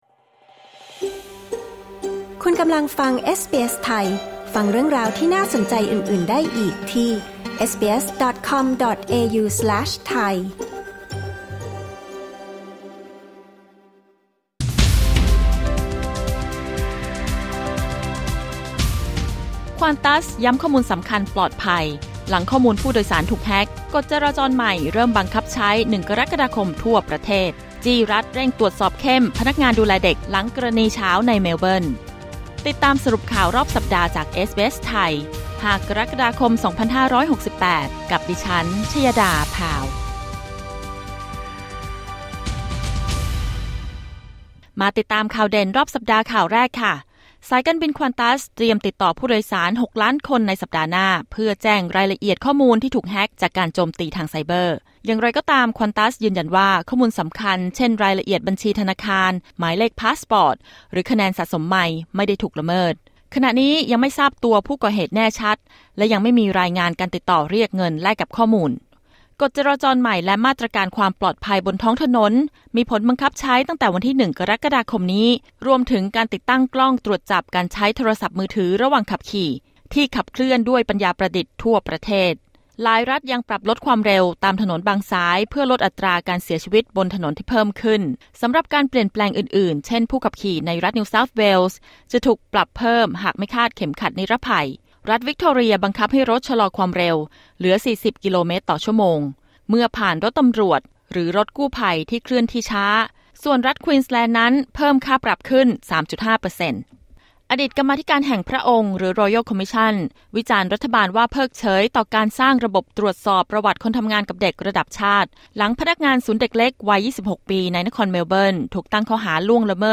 สรุปข่าวรอบสัปดาห์ 5 กรกฎาคม 2568